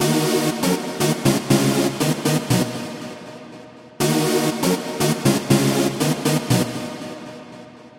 迷你909型敞篷帽
描述：一个类似于909的短开帽，具有"最小"的音质。
标签： 过滤 房子 高科技 TECHNO 精神恍惚 过滤器 TR 909 开放 俱乐部 踩镲
声道立体声